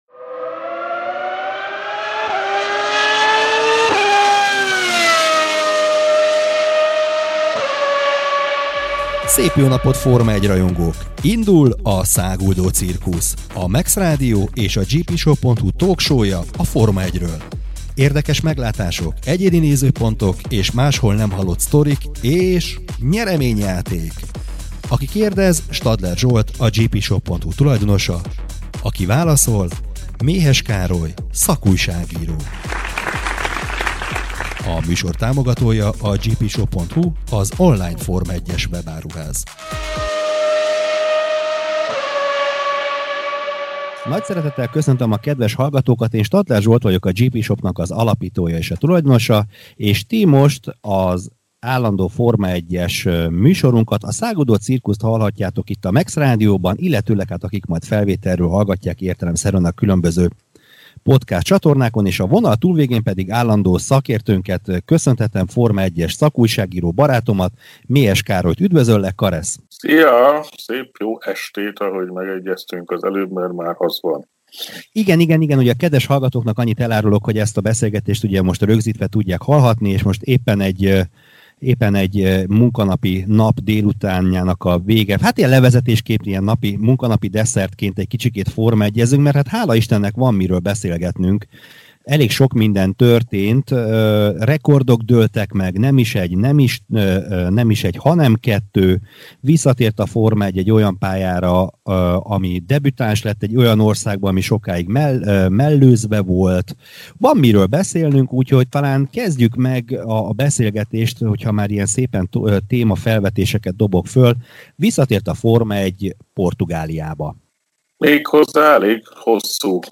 Íme a Száguldó Cirkusz újabb adása ezúttal teljes hosszában, vágatlanul.